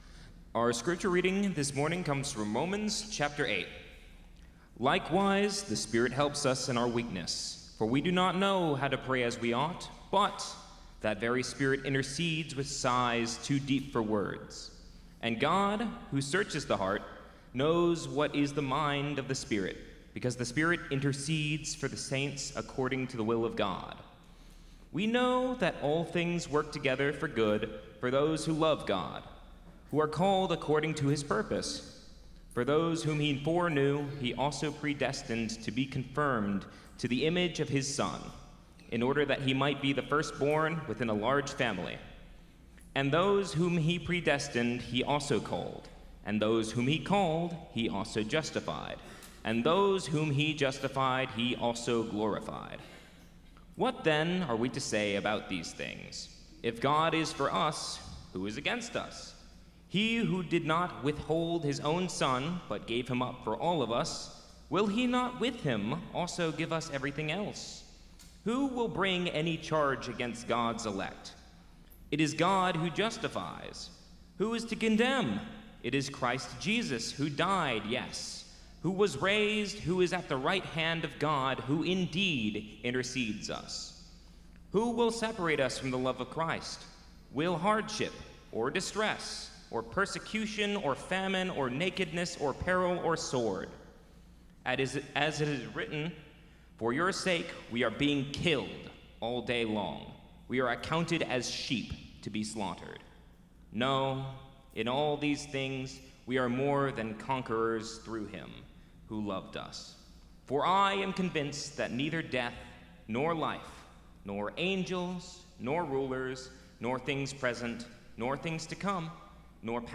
First Cary UMC's First on Chatham Sermon